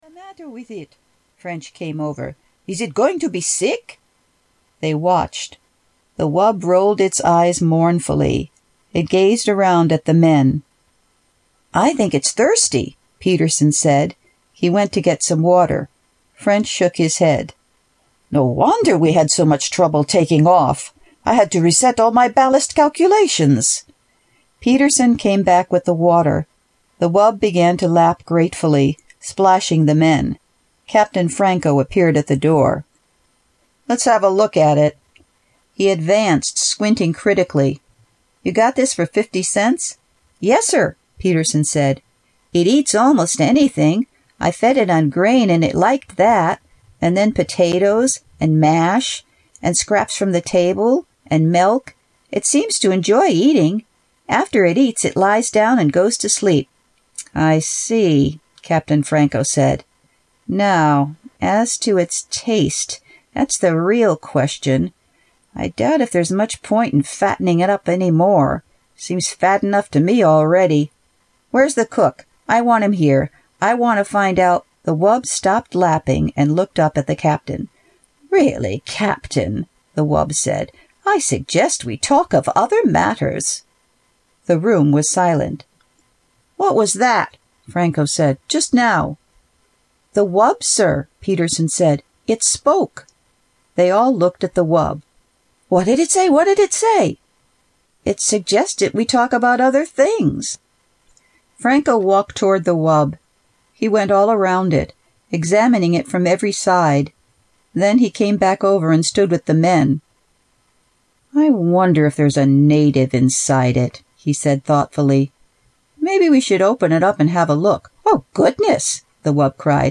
Tags: Philip K Dick Audiobooks Philip K Dick Philip K Dick Audio books Scie-Fi Scie-Fi books